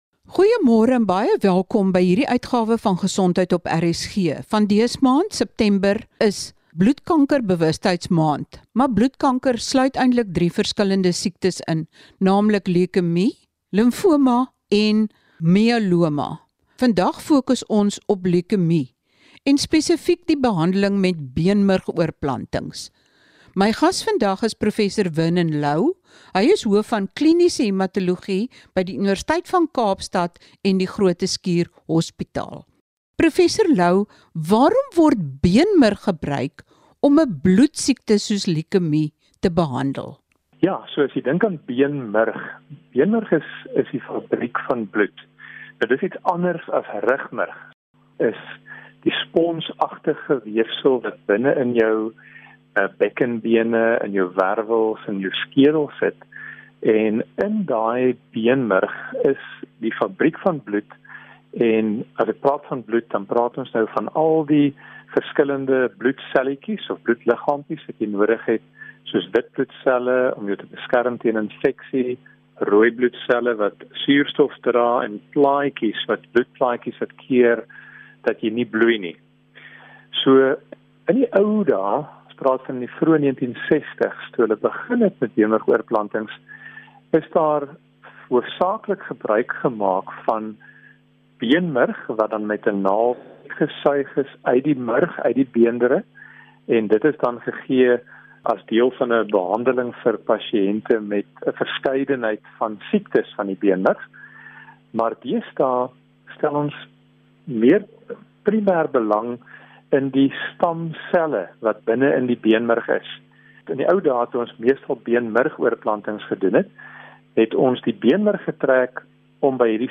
Luister hier na die volledige program: